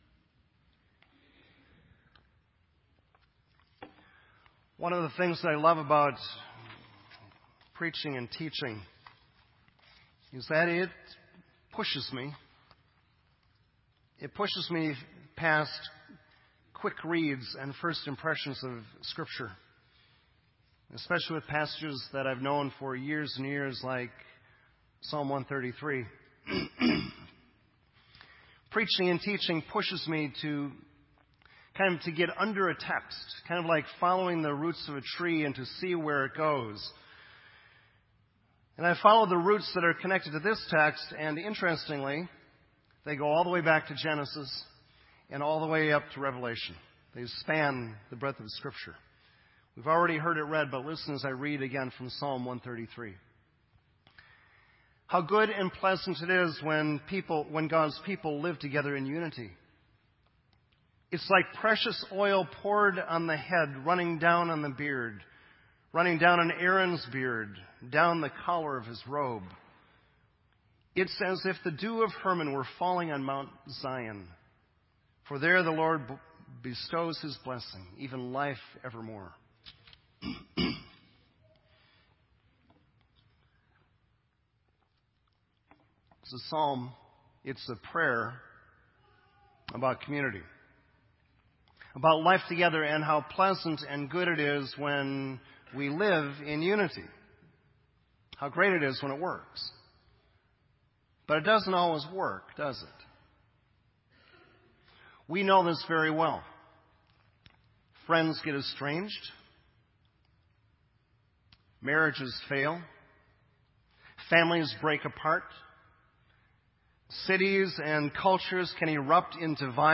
Sermon Audio